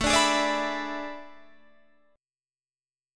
AM7sus2sus4 chord